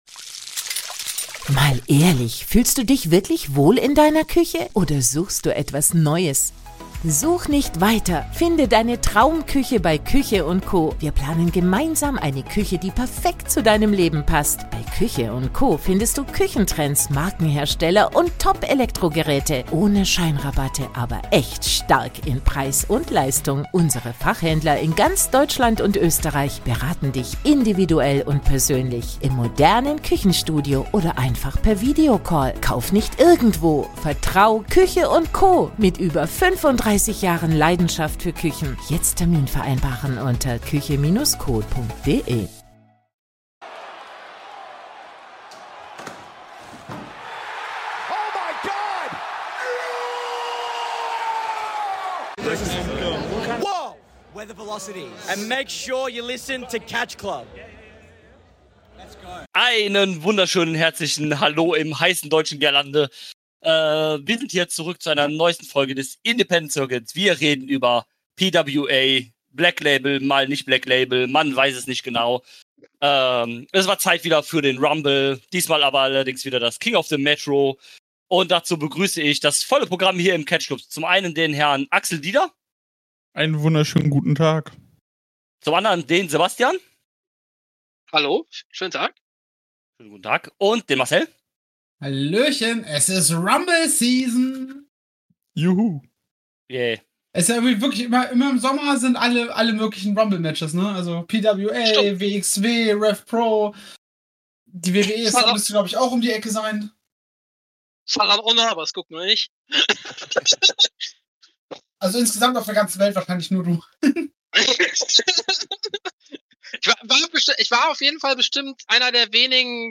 Es war mal wieder soweit, King of the Metro bei PWA. In der 4er Runde wurde über die gesamte Show gesprochen.